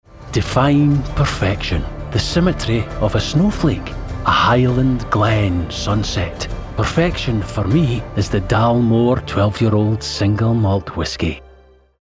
Male
English (British), English (Scottish)
Radio Commercials